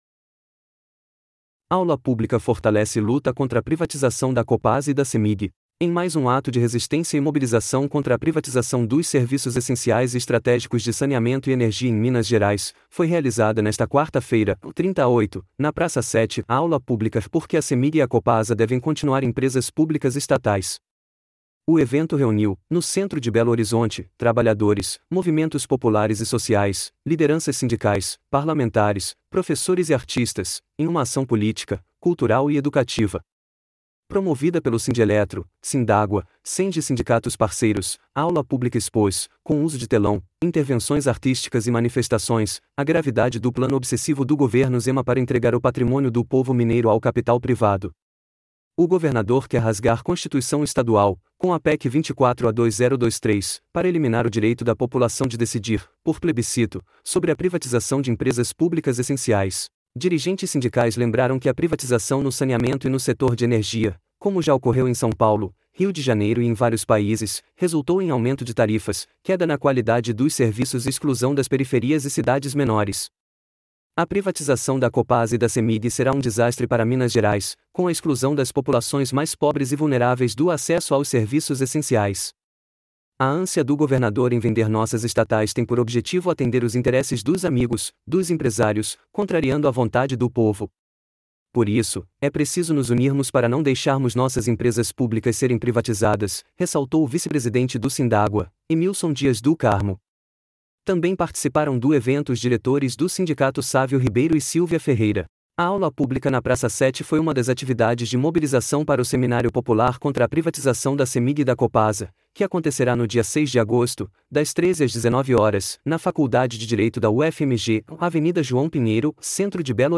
AULA PÚBLICA FORTALECE LUTA CONTRA PRIVATIZAÇÃO DA COPASA E DA CEMIG
Ação na Praça 7 reforça mobilização para seminário popular que será realizado no dia 6 de agosto